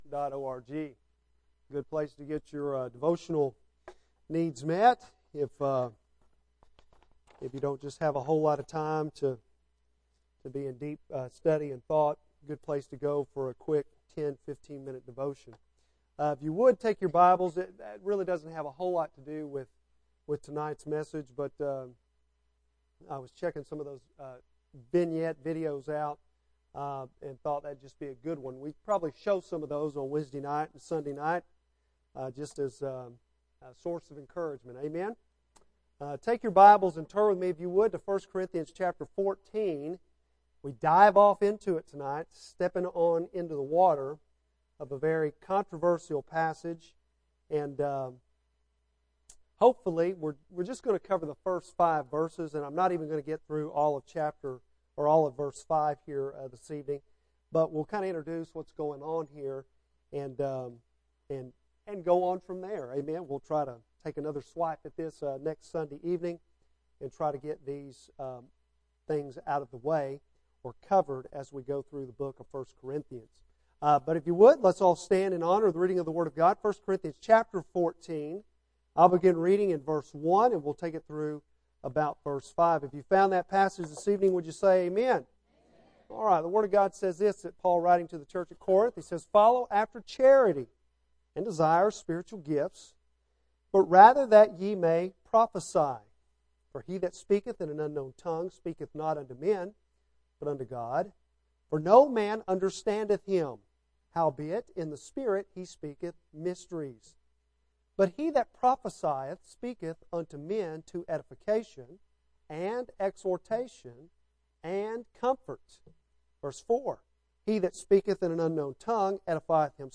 Oct 4 PM - New Hope Baptist Church